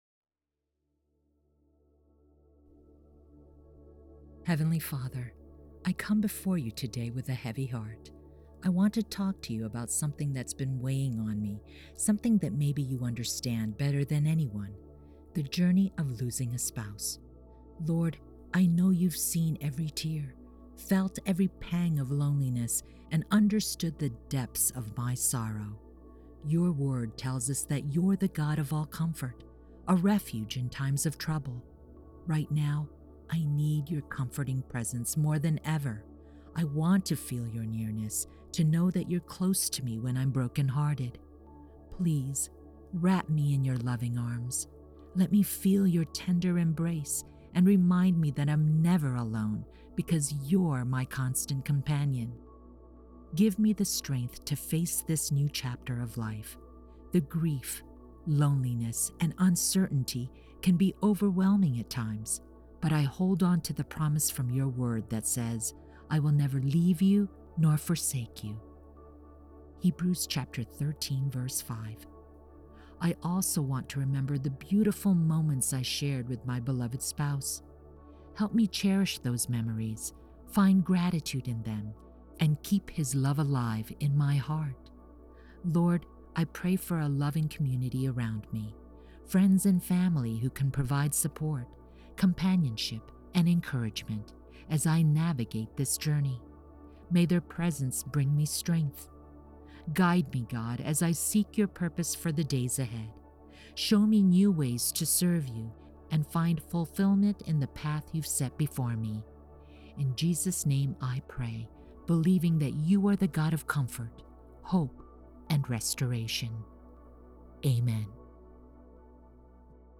May this prayer offer solace, understanding, and a comforting presence as you navigate the tender path of grief. You are not alone in this sacred space, and may these whispered words bring a measure of peace to your grieving spirit.
LosingSpousePrayer.wav